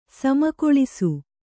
♪ samakoḷisu